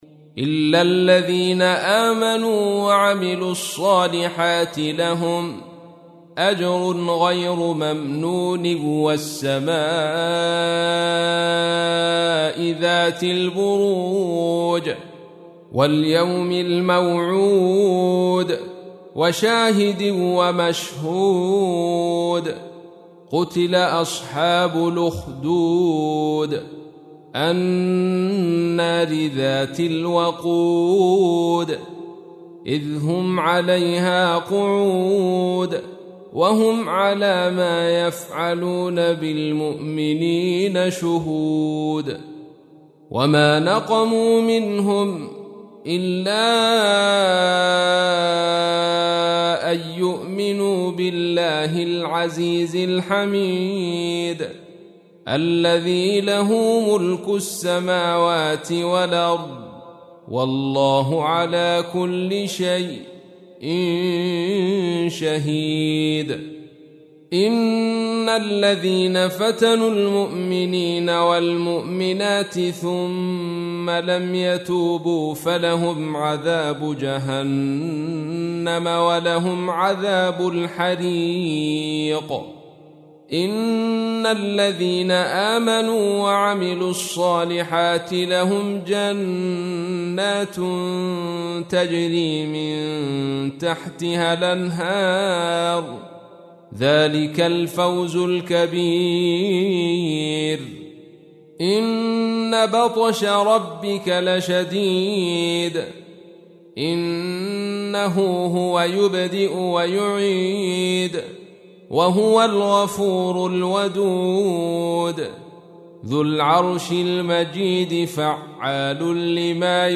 تحميل : 85. سورة البروج / القارئ عبد الرشيد صوفي / القرآن الكريم / موقع يا حسين